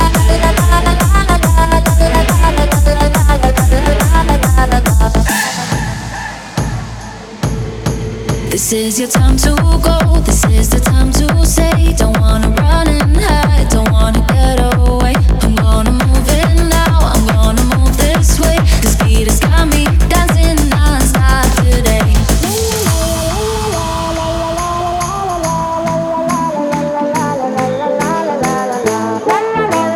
Жанр: Танцевальная музыка
# Dance